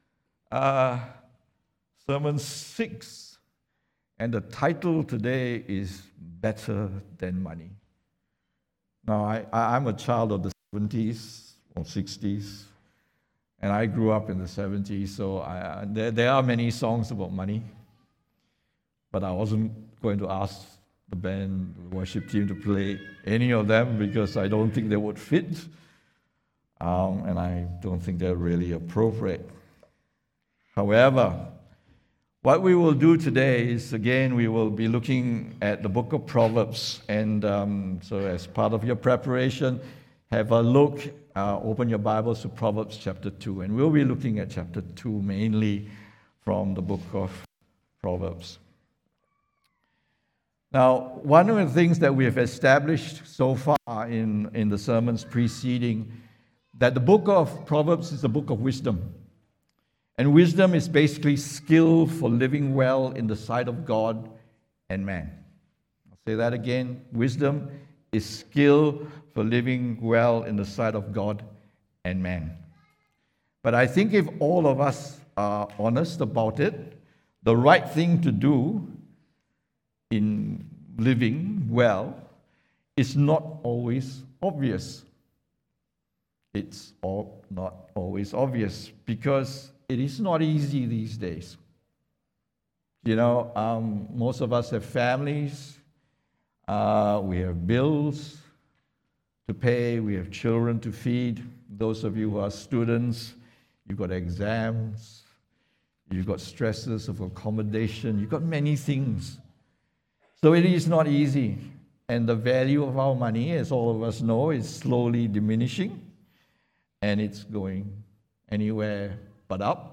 English Sermons | Casey Life International Church (CLIC)
English Worship Service - 04th Jun 2023